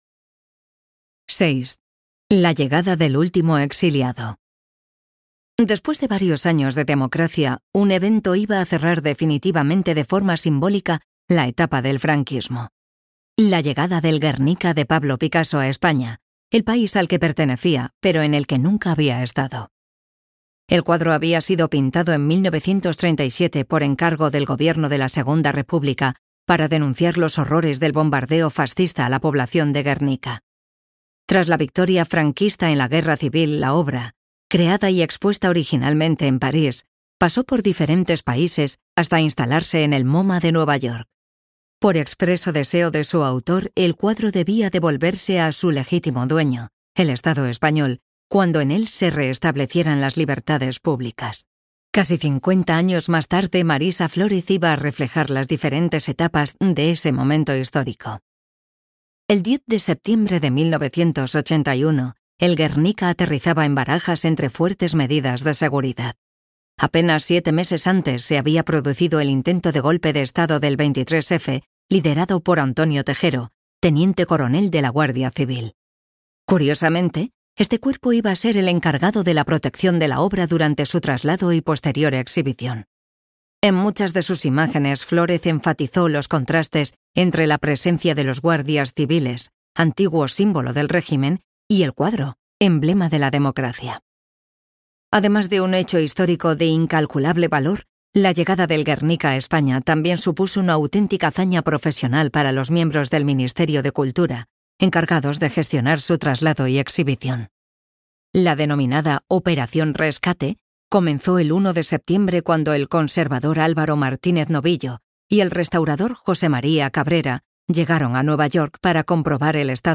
Audioguía "Marisa Flórez"